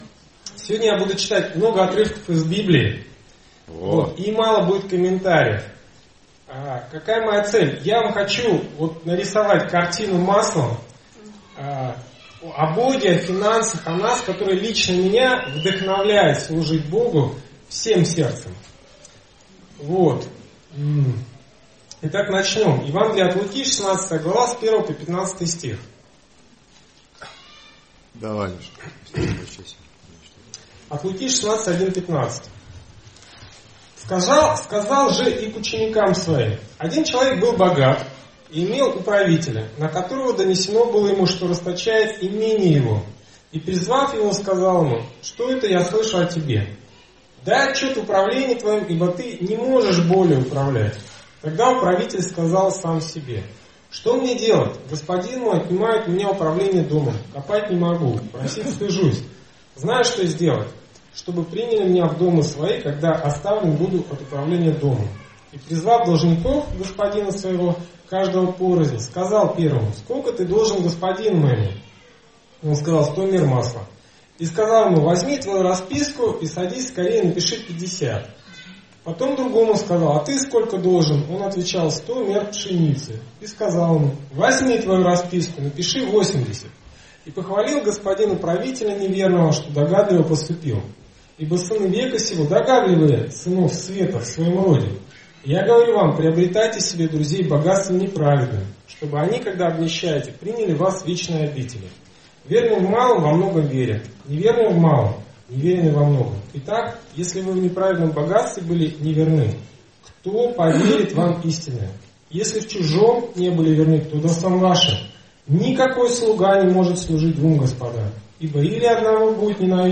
Конспект проповеди читайте далее, а аудиоверсию проповеди в формате mp3 вы можете скачать по ссылке.